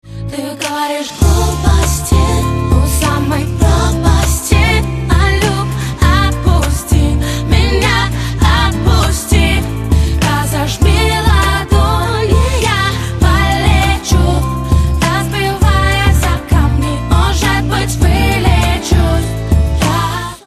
• Качество: 128, Stereo
поп
женский вокал
RnB